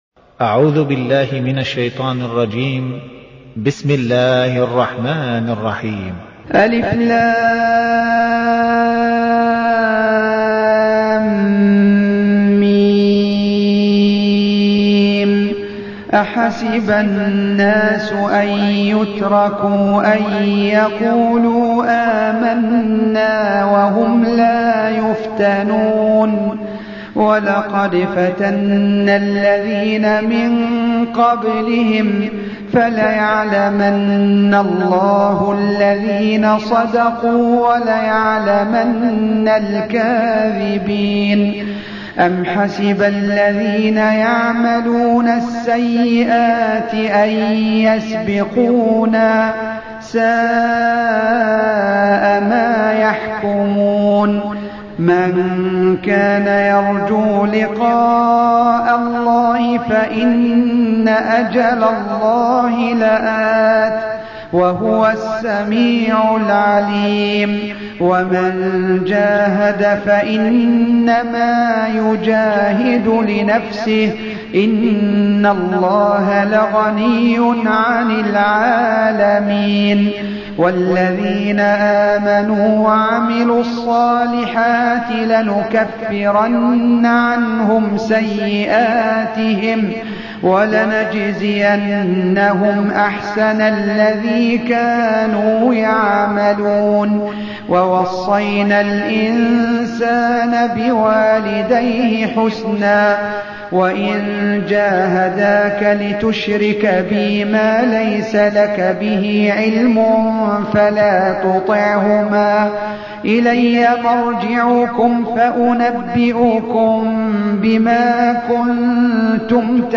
Surah Sequence تتابع السورة Download Surah حمّل السورة Reciting Murattalah Audio for 29. Surah Al-'Ankab�t سورة العنكبوت N.B *Surah Includes Al-Basmalah Reciters Sequents تتابع التلاوات Reciters Repeats تكرار التلاوات